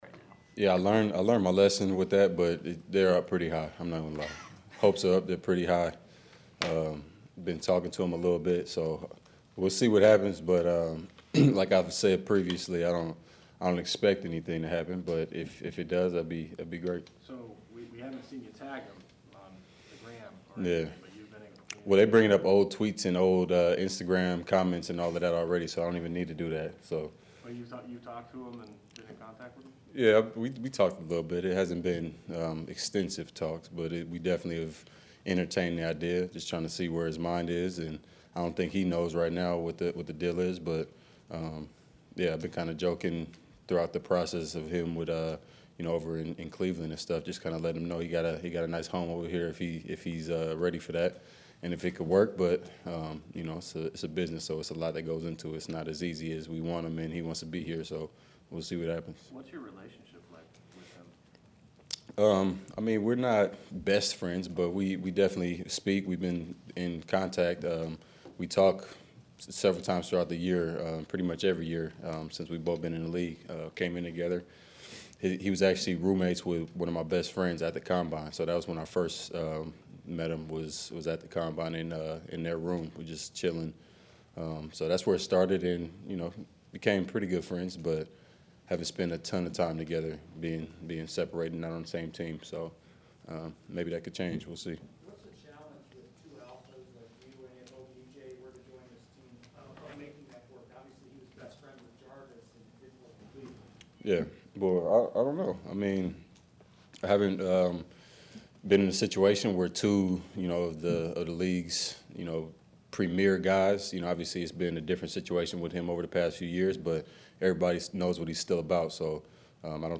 Davante Adams met reporters after practice.